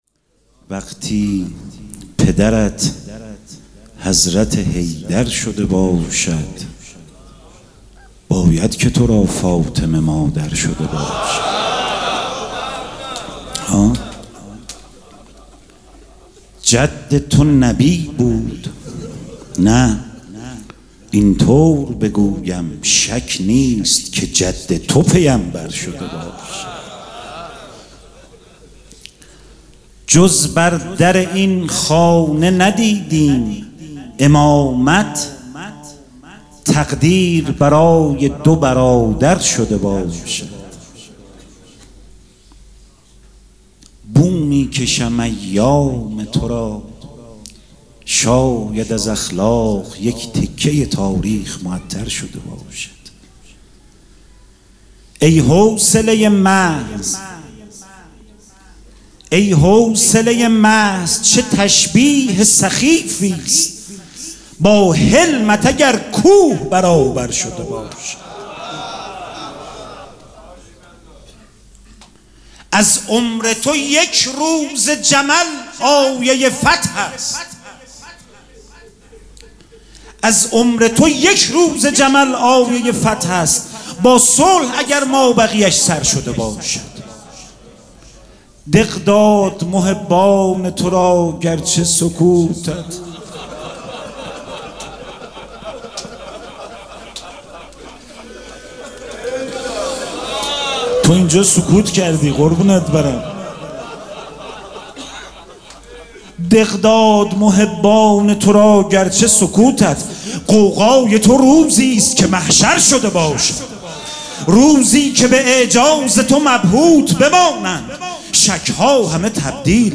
مراسم عزاداری شب شهادت امام حسن مجتبی(ع) پنجشنبه ۹ مرداد ۱۴۰۴ | ۶ صفر ۱۴۴۷ ‌‌‌‌‌‌‌‌‌‌‌‌‌هیئت ریحانه الحسین سلام الله علیها
سبک اثــر شعر خوانی